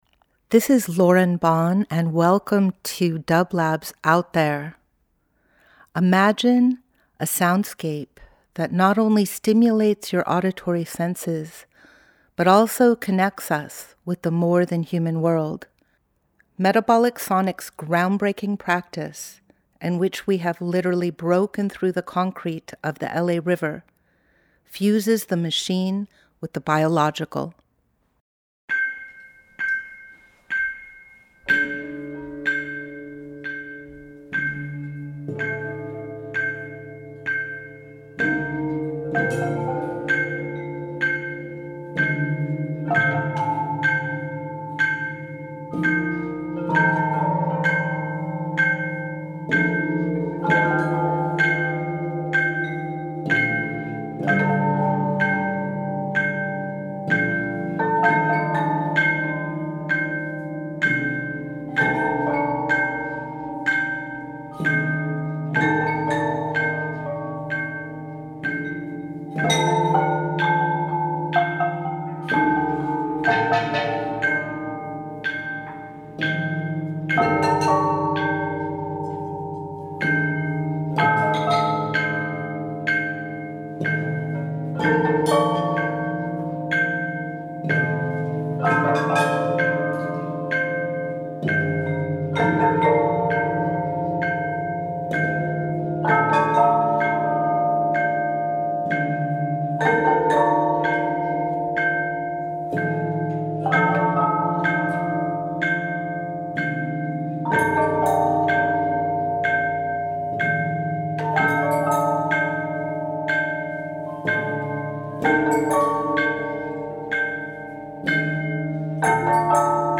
Each week we present a long-form field recording that will transport you through the power of sound.
Sonic Division made all the instruments in the Anabolic Orchestra, fabricating 90 gongs and chimes ranging from 8” – 30” in diameter. The public was invited to the studio to perform every week. The approach to the music was inspired by the ancient tradition of the gong and chime orchestras in Southeast Asia.
Metabolic Sonics Metabolic Studio Out There ~ a field recording program 08.28.25 Ambient Field Recording Instrumental Minimal Voyage with dublab and Metabolic into new worlds.